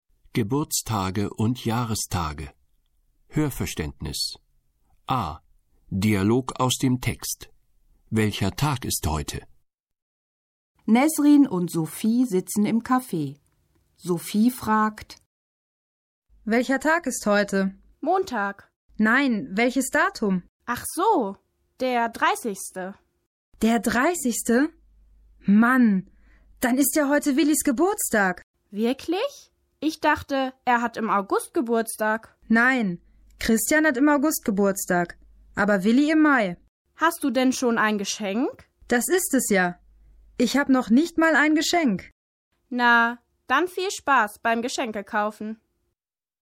Dialog aus dem Text: Welcher Tag ist heute? (743.0K)